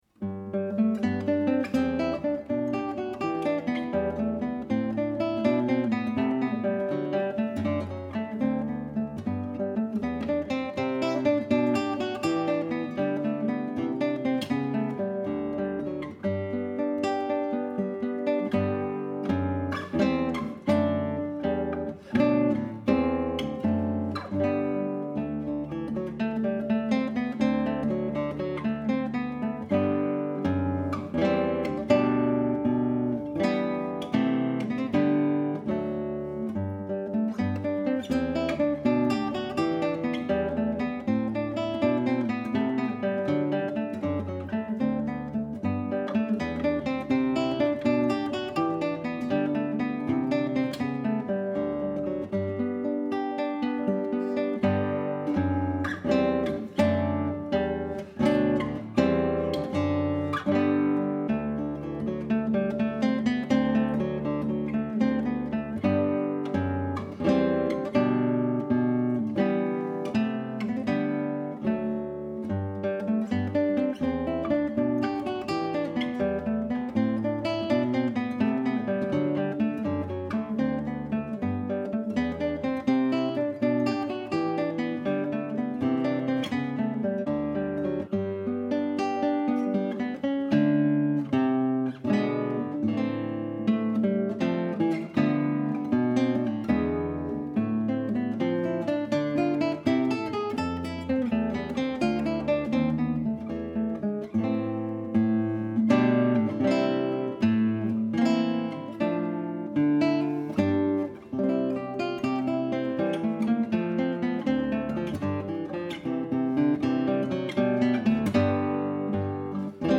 arrangements for guitar
This one utilizes the dropped D tuning to help maintain the integrity of the bass line in the transcription.